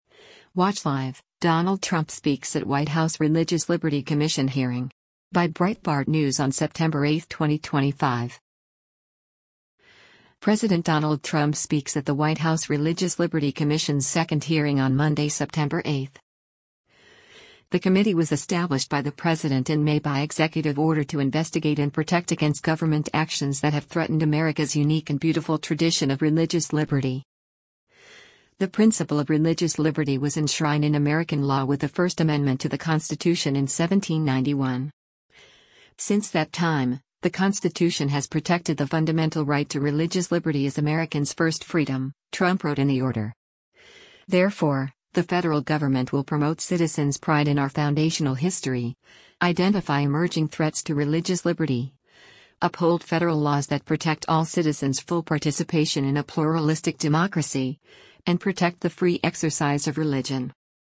President Donald Trump speaks at the White House Religious Liberty Commission’s second hearing on Monday, September 8.